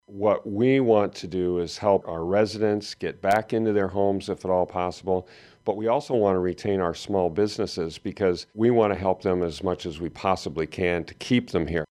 SPENCER MAYOR STEVE BOMGAARS SAYS 30 PERCENT OF SPENCER BUSINESSES HAVE BEEN IMPACTED BY THE DISASTER.